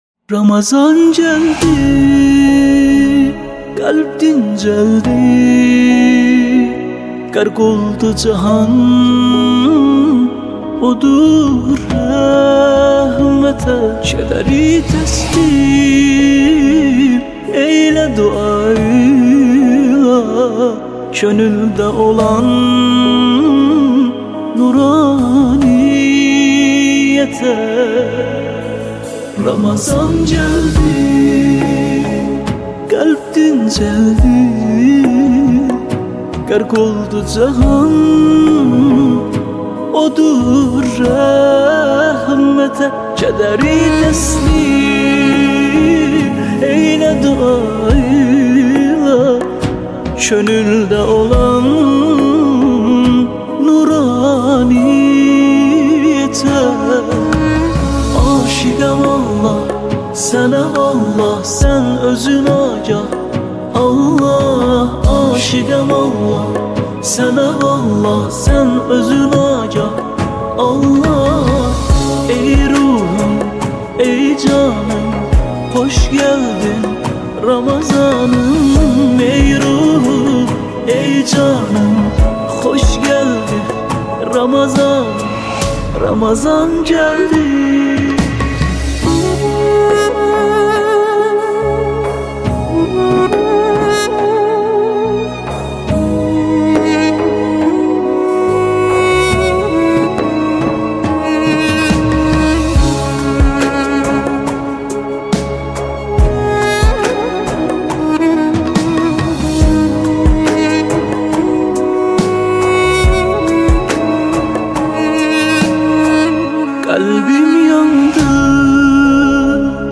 نماهنگ آذری